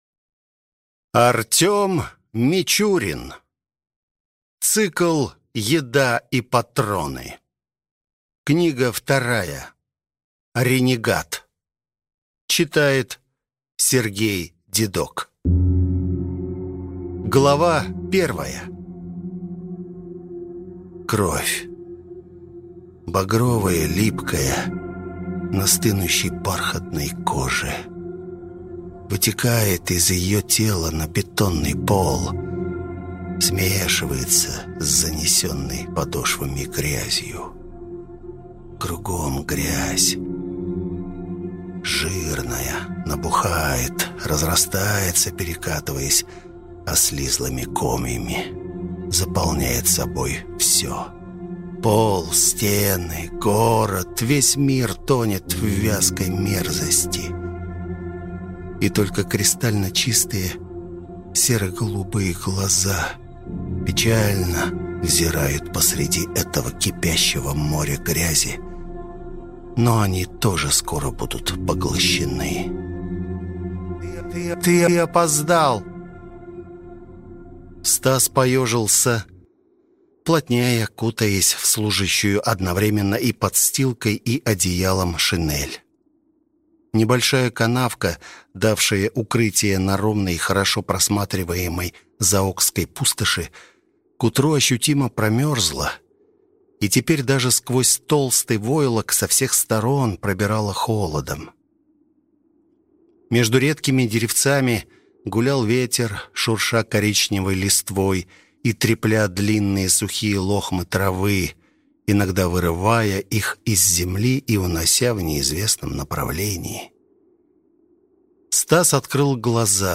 Аудиокнига Ренегат - Мичурин Артём Александрович - Скачать бесплатно полную версию, слушать онлайн